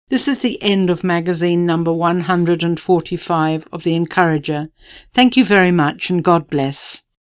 Genre: Speech